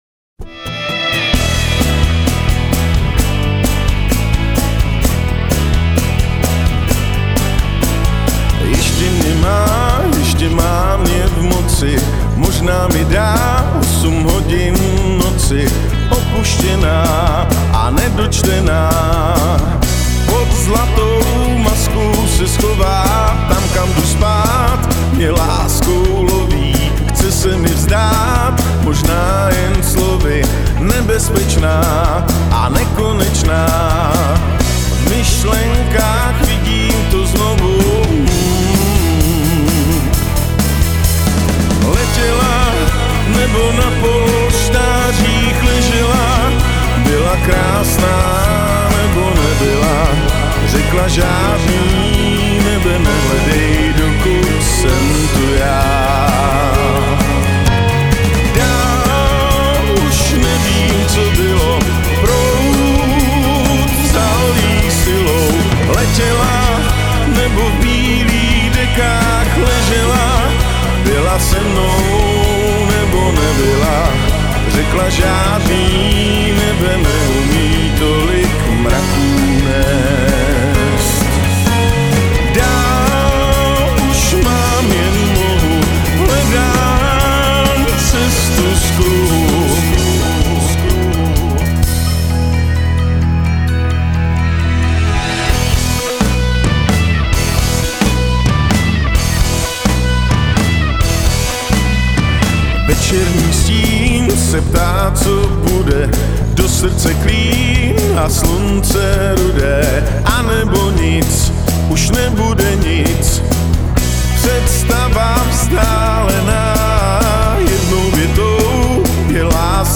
ve svižné a chytlavým refrénem opatřené skladbě